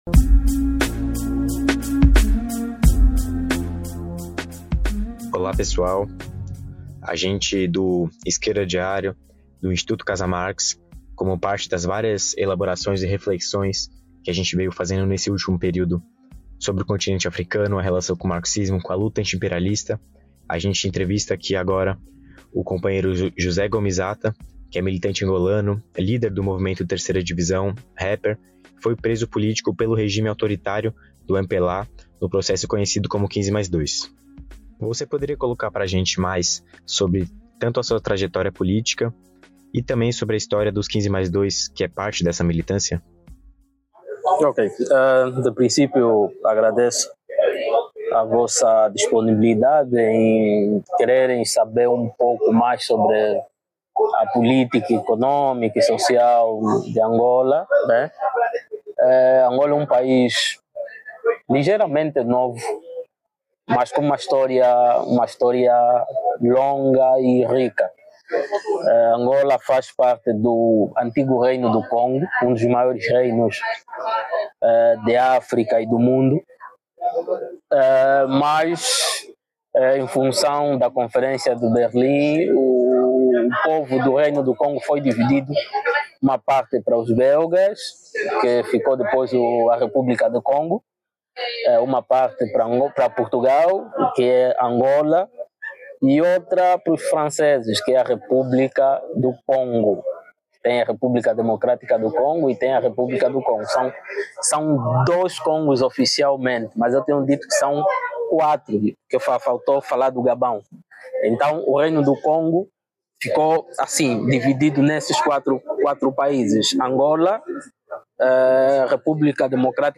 Instituto Casa Marx / Luta de classes em Angola: Entrevista